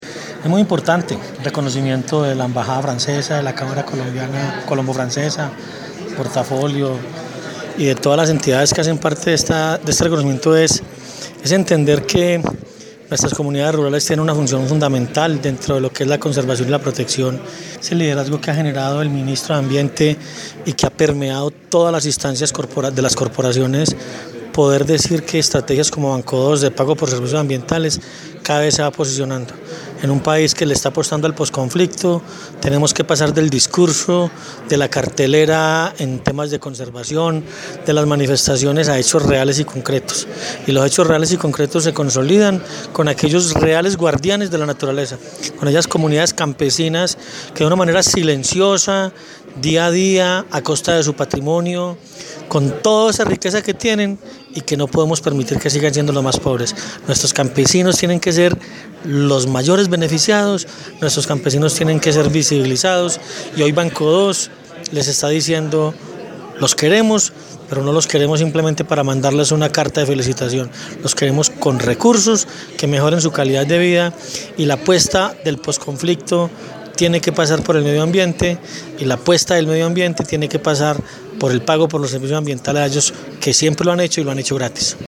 función fundamental dentro de lo que es la conservación y la protección, es el liderazgo que ha generado el Ministro de Ambiente y que ha permeado todas las instancias de las corporaciones, poder decir que estrategias como BanCO2 de pagos por servicios ambientales cada vez se va posicionando”, Expresó el Director General de Cornare.